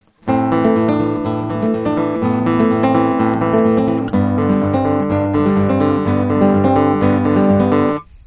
In der linken Hand geht es nur um einen A-Moll Akkord, der mit dem "d" (3.Bund h-Saite 4. Finger) umspielt wird und um einen G-Dur Akkord, wo ein "c" (1. Bund h-Saite 1. Finger) und das "d" eingeflochten werden.